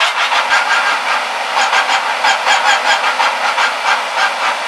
rr3-assets/files/.depot/audio/sfx/transmission_whine/tw_offlow.wav